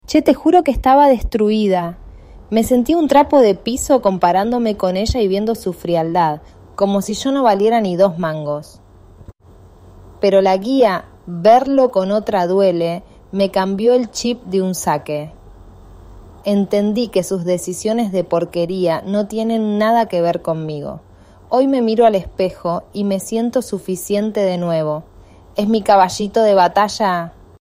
Testimonio-3.mp3